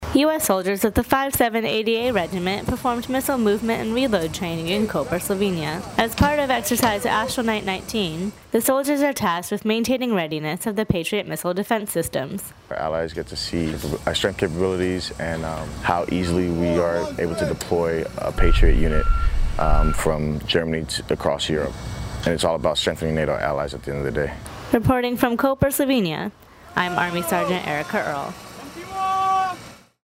KOPER, Slovenia—U.S. Soldiers from the 5th Battalion 7th Air Defense Artillery Regiment held missile movement and reload training, as well as an alert state assumption practice, in Koper, Slovenia, June 3 2019.